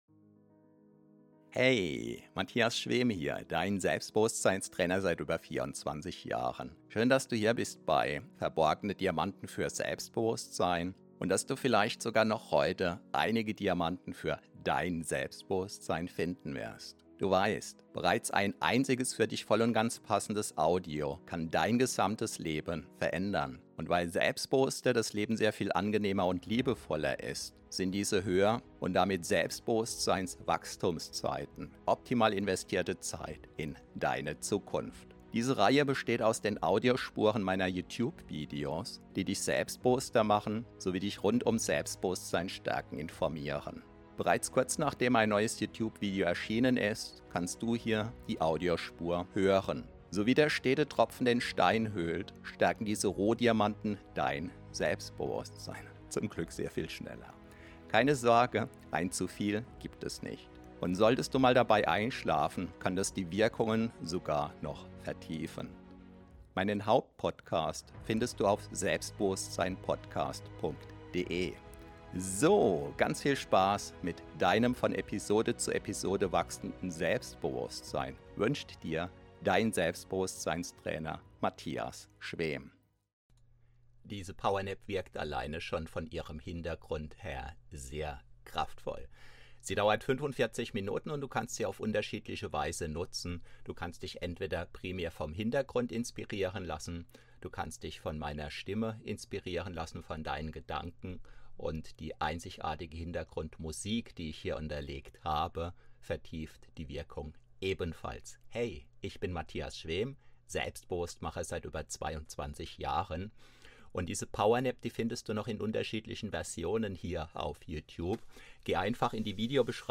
Power Nap 45 min Powernapping Mittagsschlaf Hypnose Meditation Powerschlaf Powernap deutsch ~ Verborgene Diamanten Podcast [Alles mit Selbstbewusstsein] Podcast